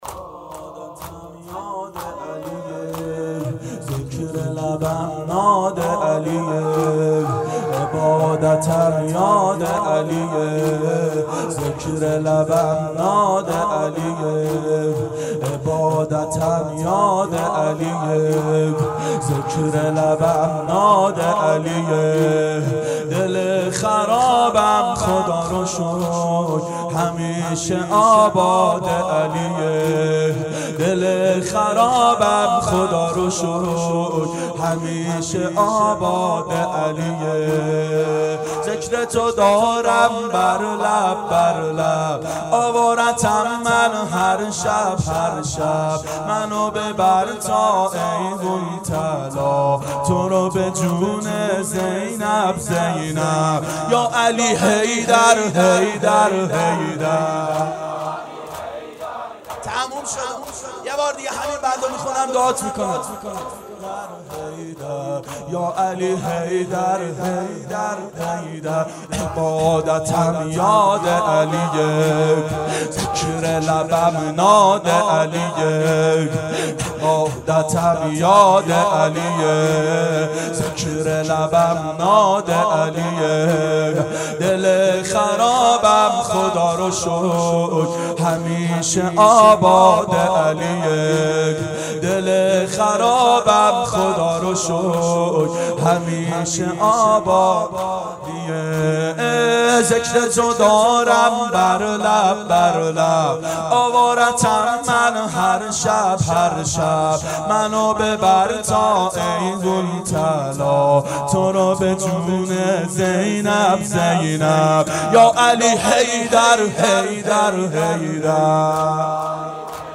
دهه اول صفر سال 1390 هیئت شیفتگان حضرت رقیه س شب اول